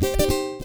Lick Rnb 2.wav